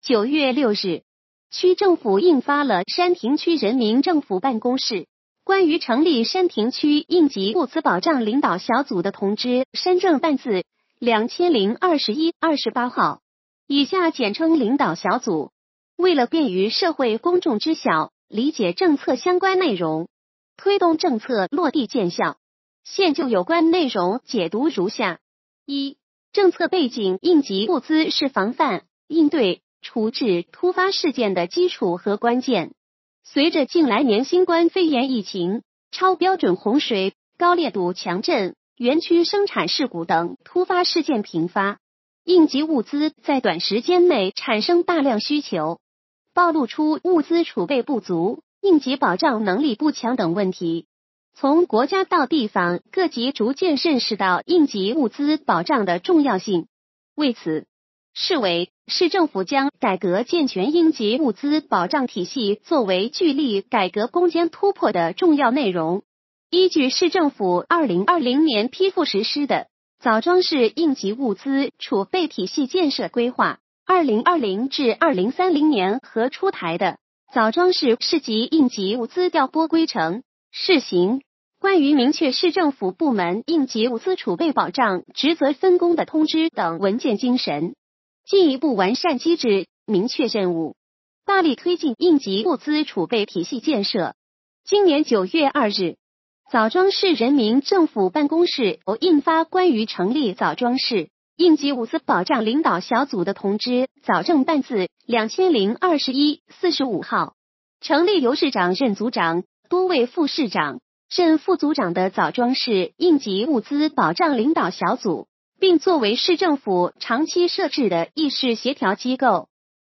语音解读：山亭区人民政府办公室关于成立山亭区应急物资保障领导小组的通知